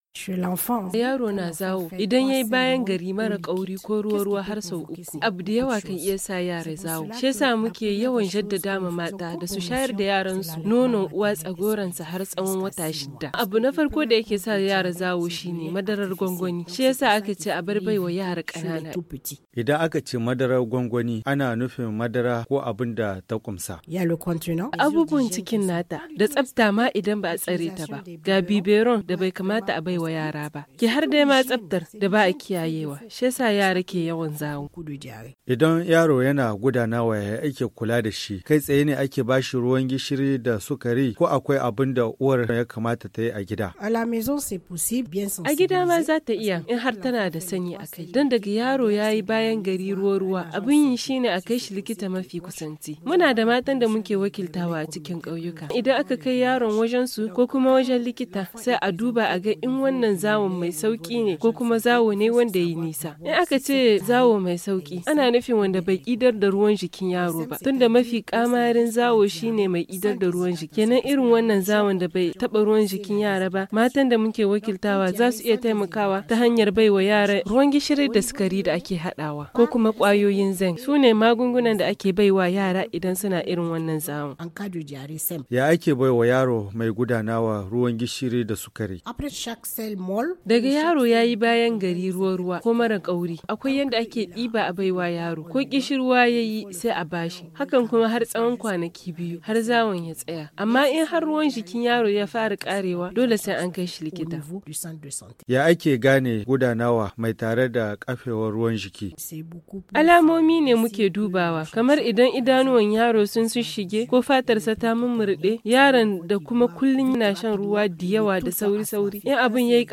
Le médecin explique que la conséquence directe d’une diarrhée sans prise en charge est la déshydratation sévère, qui conduit souvent à la mort.